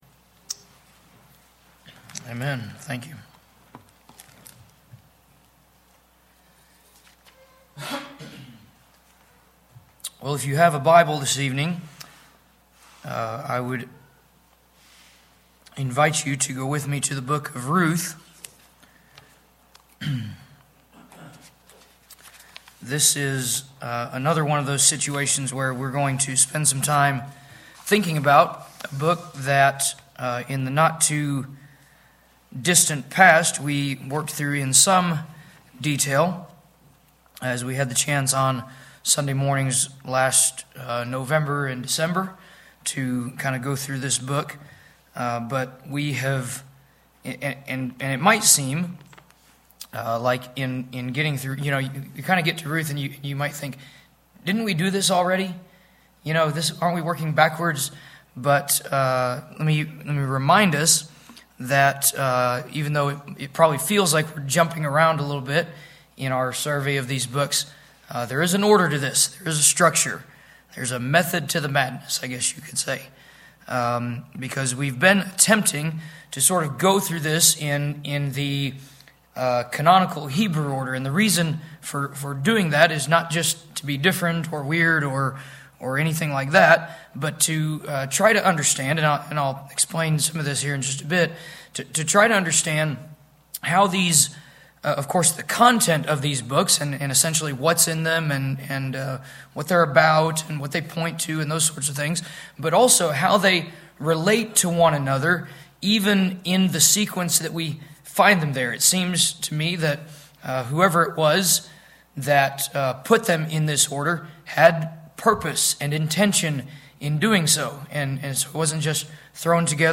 A Theological Survey Old Testament Ruth Bible Study